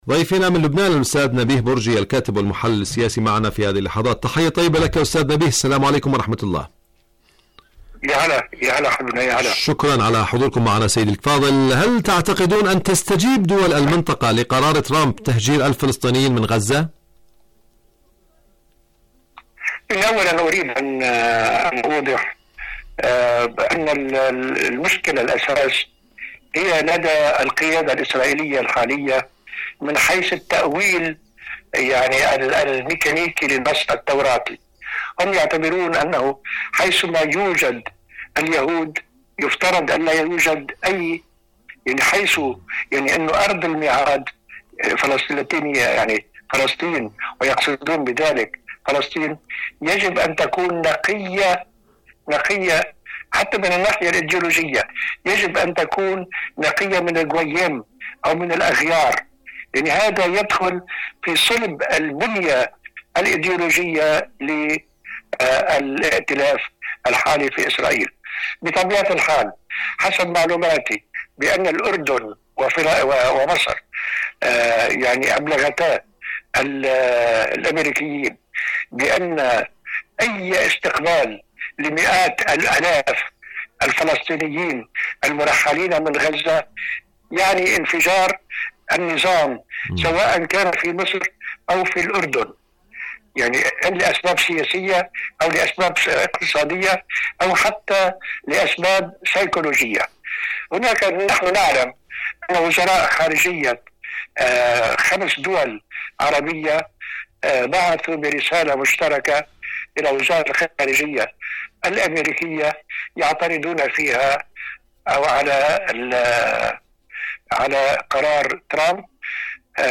مقابلات برامج إذاعة طهران العربية برنامج حدث وحوار الكيان الصهيوني مقابلات إذاعية هل يرى مشروع تهجير الفلسطينيين النور؟